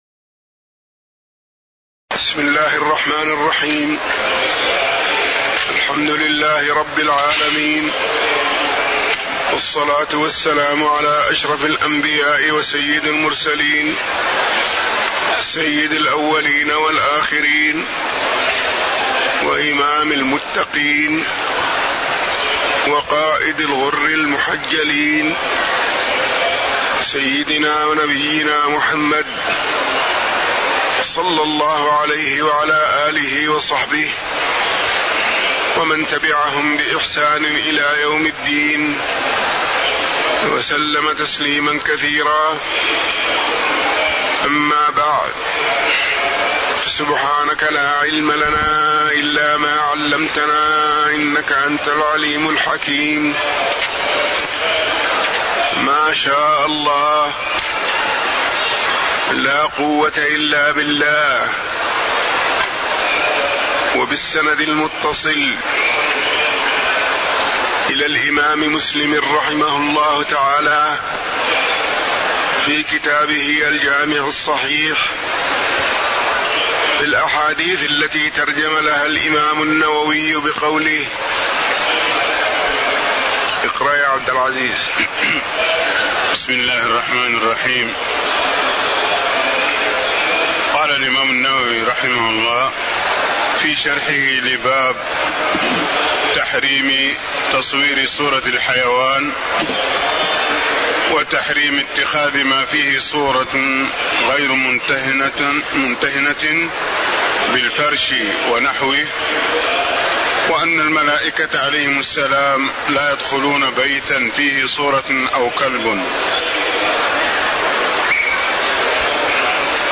تاريخ النشر ٢٤ شوال ١٤٣٦ هـ المكان: المسجد النبوي الشيخ